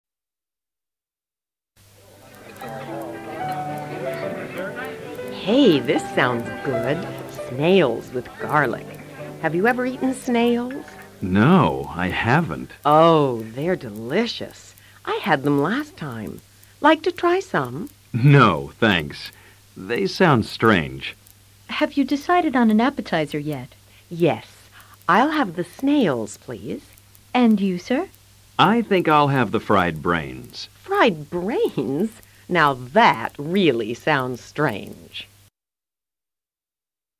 Caroline y Arthur van al restaurante y eligen platos "especiales". Escucha con atención y repite luego el diálogo.